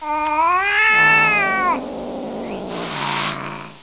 1 channel
catyowl.mp3